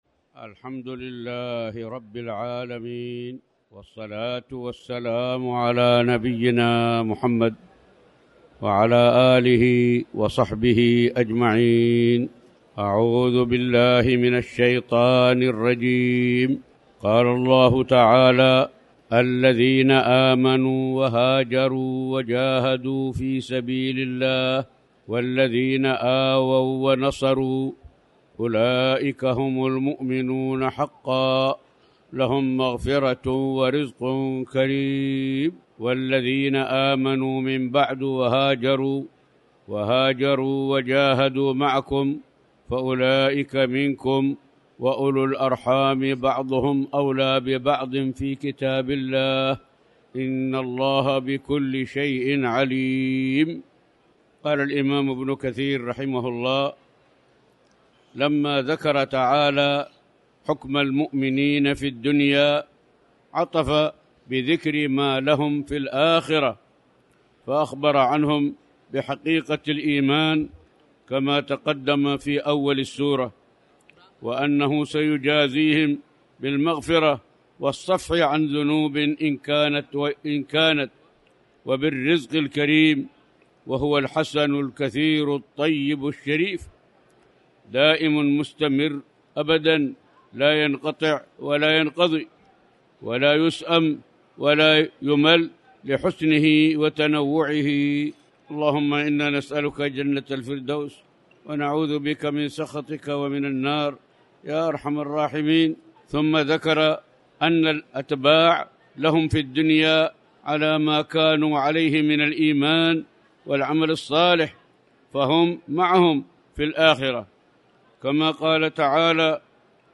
تاريخ النشر ٩ رمضان ١٤٣٩ هـ المكان: المسجد الحرام الشيخ